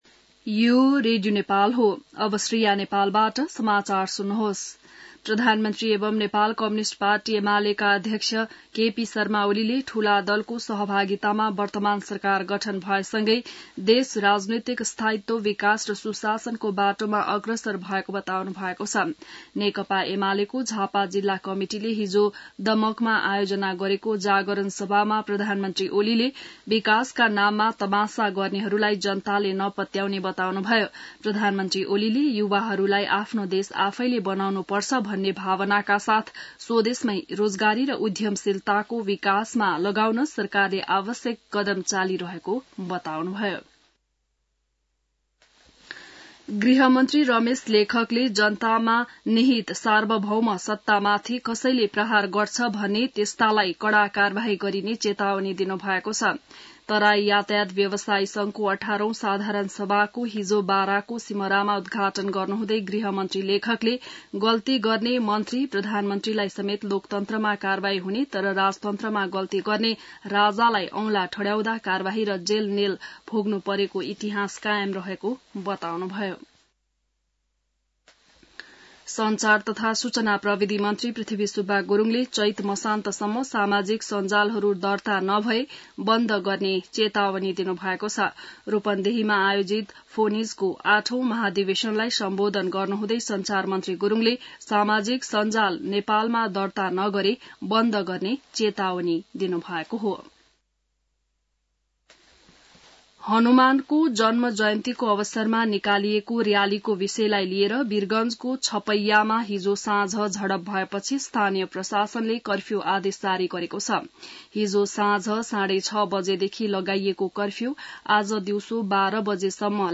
बिहान ६ बजेको नेपाली समाचार : ३० चैत , २०८१